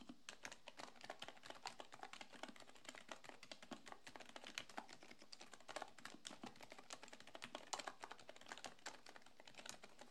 Każde naciśnięcie klawisza jest płynne, bez zbędnego klekotu czy wibracji.
Pod względem akustyki Epomaker x Aula EA75 Max i zamontowane tu przełączniki są naprawdę bardzo ciche. Klawiatura nie jest jednak kompletnie wyciszona. Generuje ona ciepłe, kremowe brzmienie, które daje wyraźne potwierdzenie aktywacji klawisza. Nawet przy mocniejszym naciskaniu, charakterystyczny „klik” rozchodzi się równomiernie, bez rezonansu czy nieprzyjemnego echa.
EPOMAKER-x-AULA-EA75-Max-dzwiek.mp3